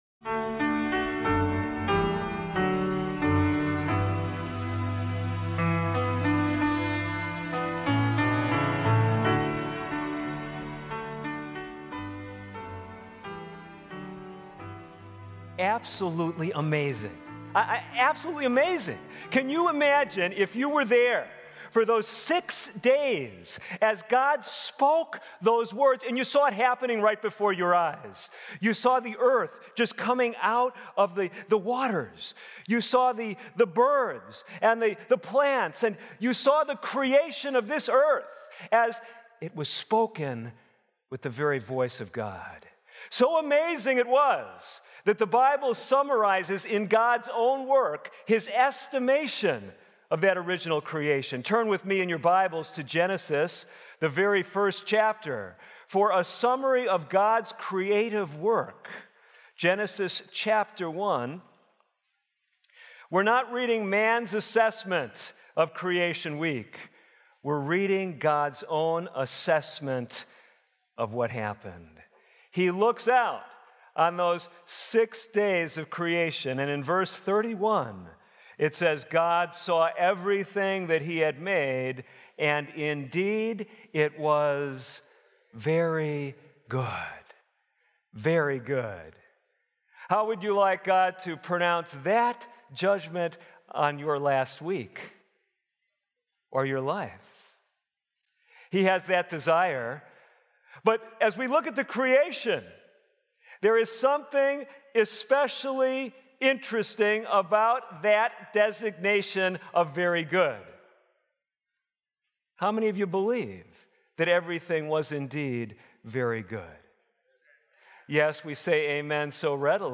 This sermon explores the deep biblical symbolism of the “trees” of Scripture—from Eden’s test of choice to the cross of Christ and the restored Tree of Life. By placing human trials within a cosmic conflict and God’s redemptive plan, it reveals how free will, testing, and sacrifice lead ultimately to restoration, worship, and eternal life with God.